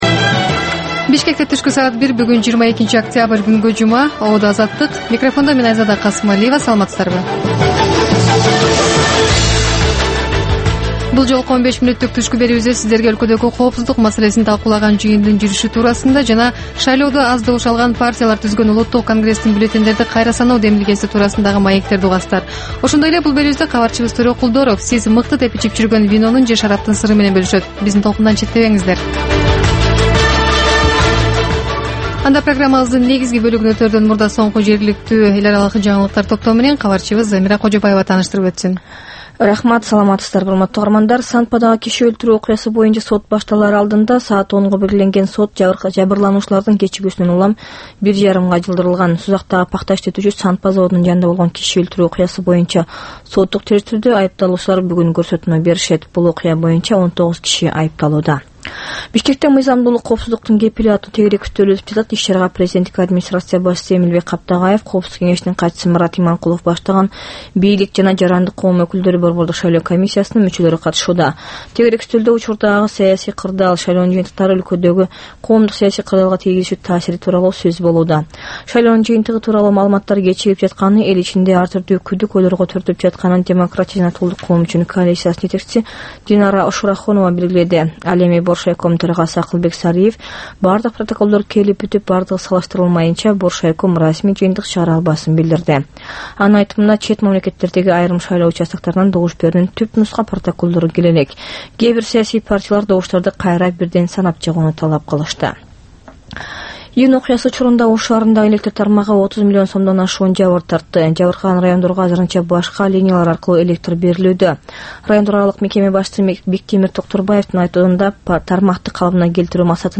Түшкү саат 1деги кабарлар